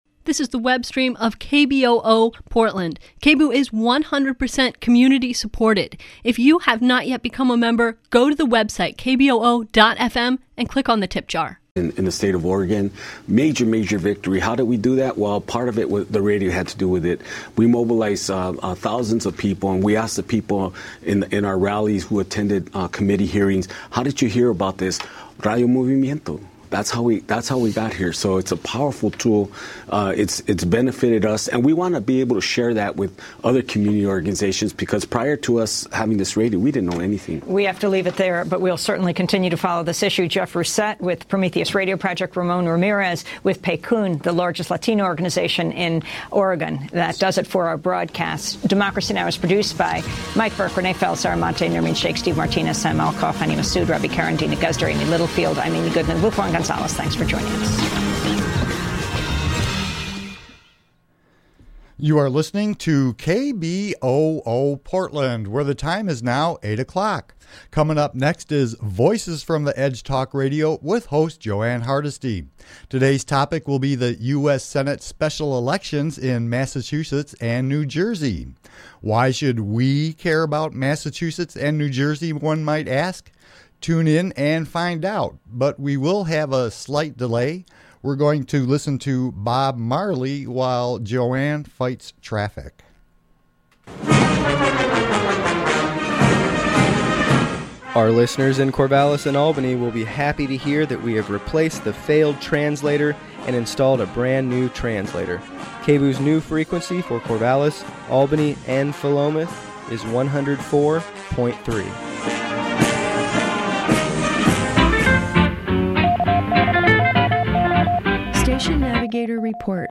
Progressive talk radio from a grassroots perspective